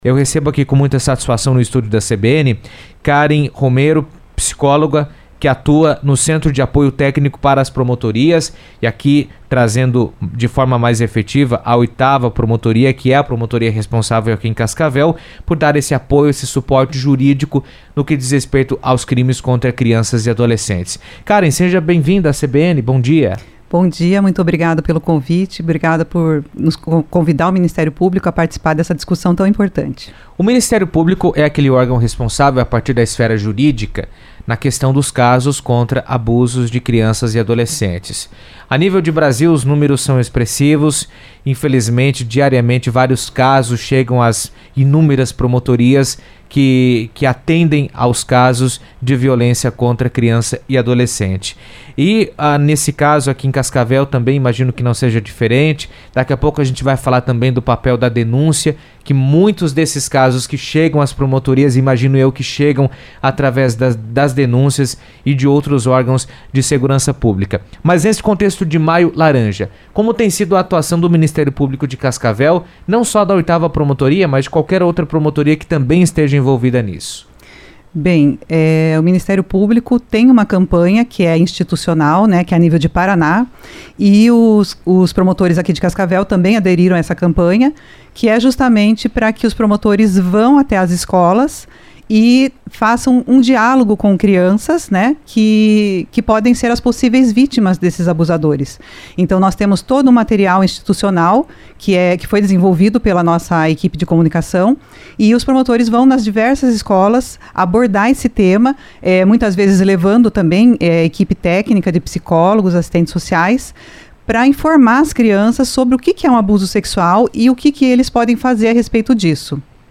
esteve na CBN Cascavel falando da atuação da 8ª Promotoria de Justiça de Cascavel no combate ao abuso sexual de crianças e adolescentes.